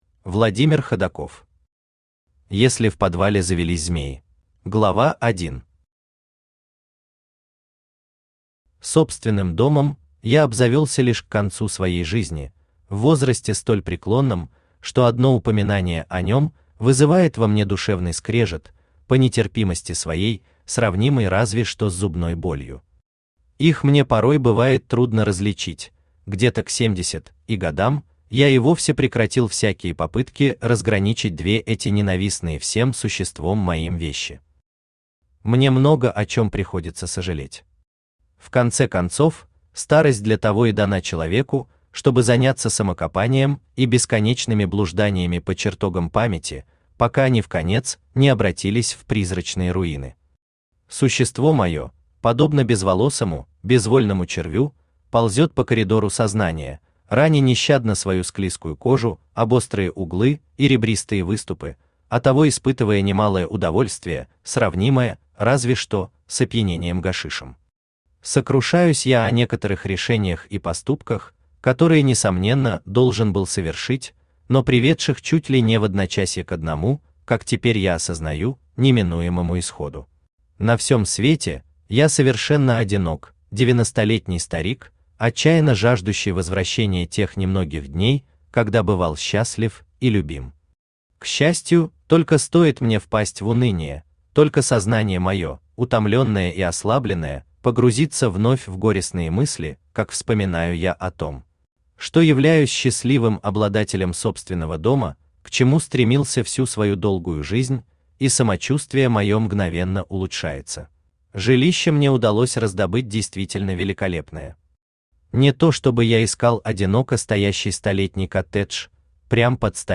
Аудиокнига Если в подвале завелись змеи | Библиотека аудиокниг
Aудиокнига Если в подвале завелись змеи Автор Владимир Ходаков Читает аудиокнигу Авточтец ЛитРес.